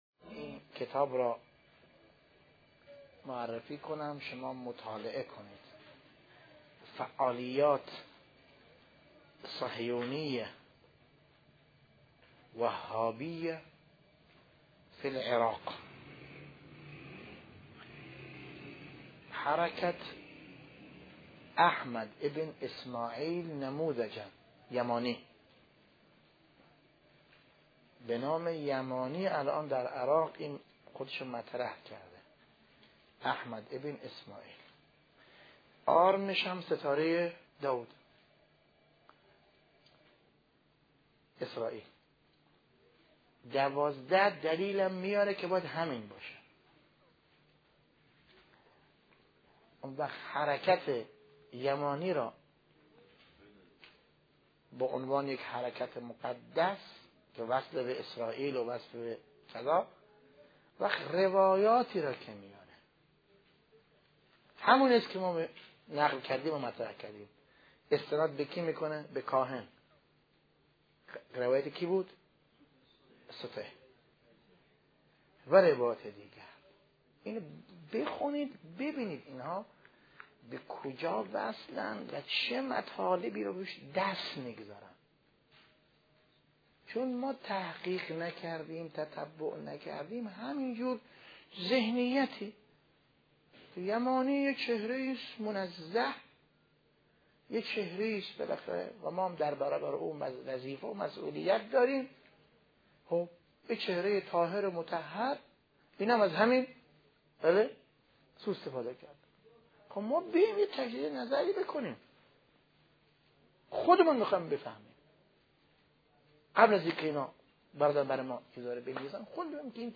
بحث خارج مهدویت - بحث یمانی ج ۷